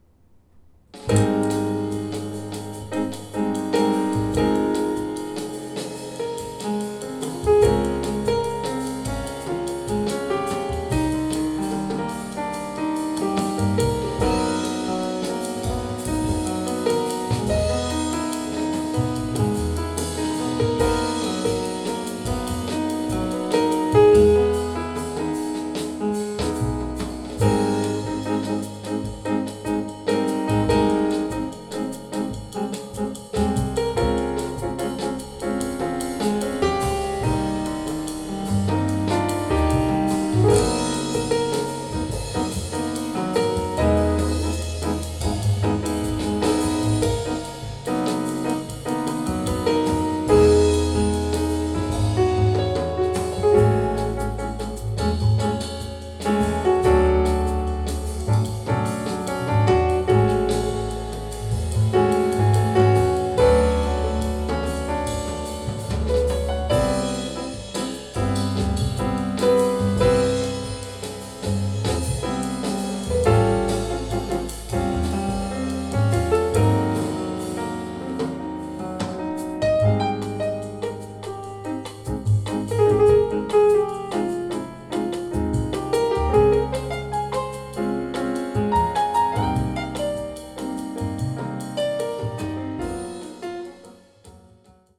アンプ Nmode X-PM100-SE
スピーカー DYNAUDIO Confidence C1
クロック接続していない状態です。